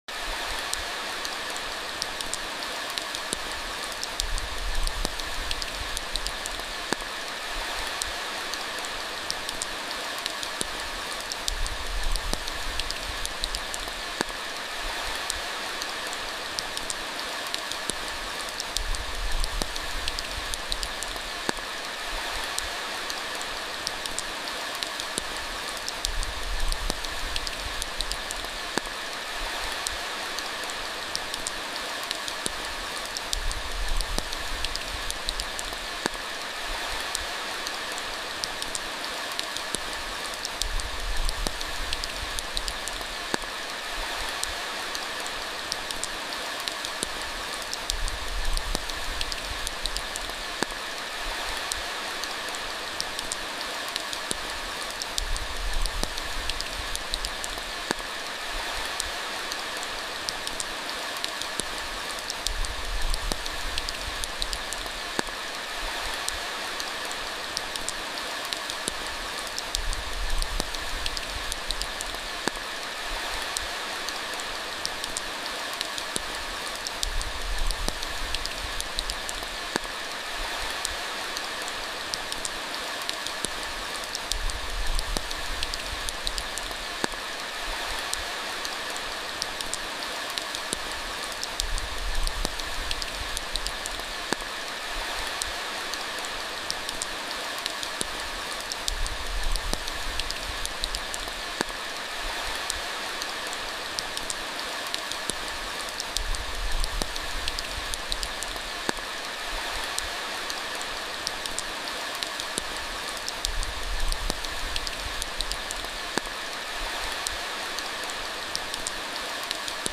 Lose yourself in the calm rhythm of rain falling on the surface of a peaceful alpine lake. Surrounded by misty Austrian mountains and the quiet stillness of nature, this is the perfect escape for relaxation, sleep, or mindful moments.